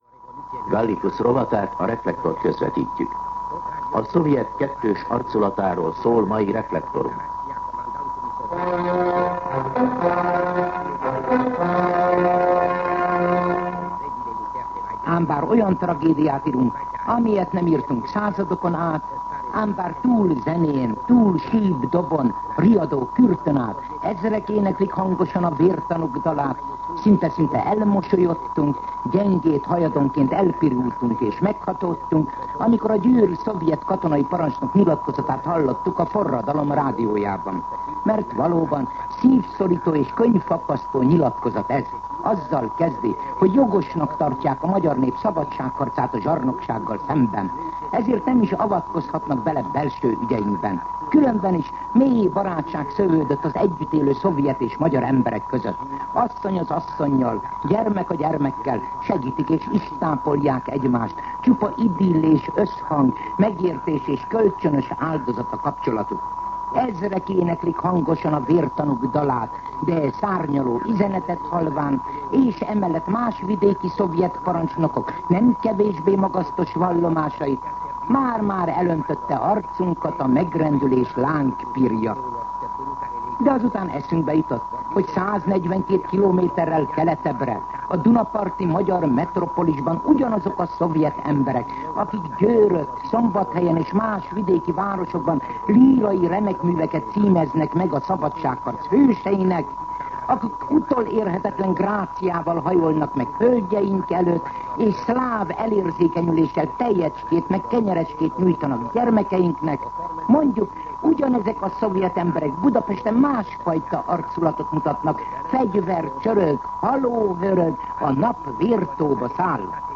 MűsorkategóriaKommentár